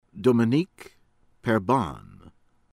PASQUA, CHARLES SHAHRL   PAHS-KWAH